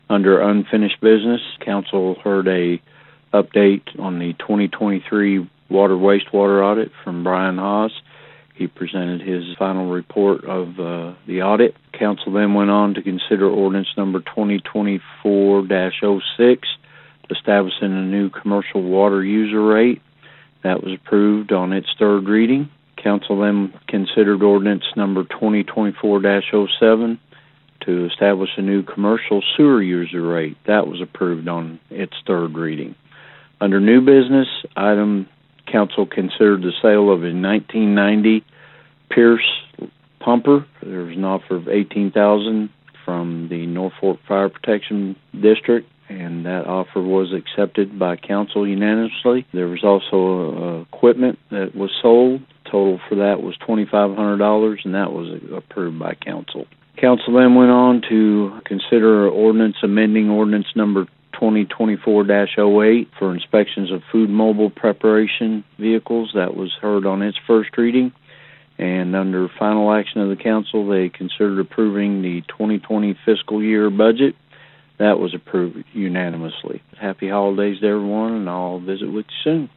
Mayor Jeff Braim shared the following recap.